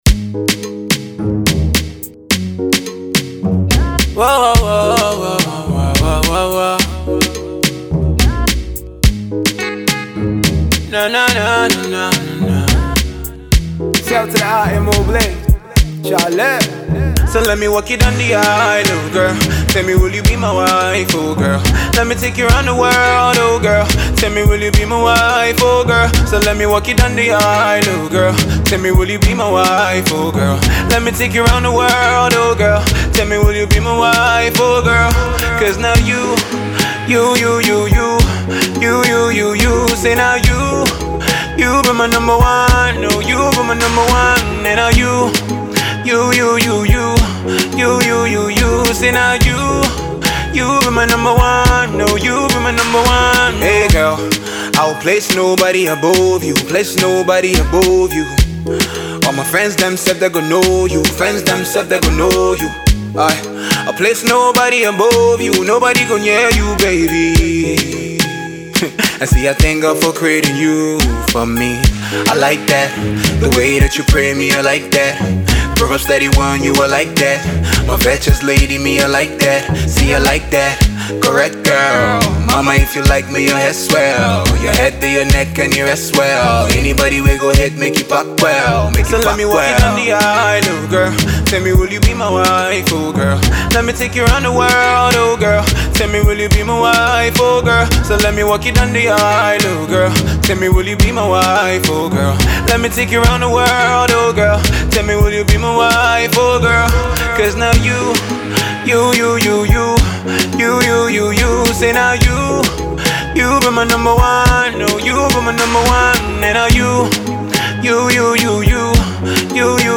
Love Song
This afro pop tune